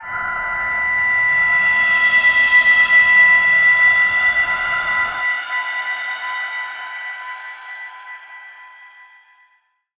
G_Crystal-B6-f.wav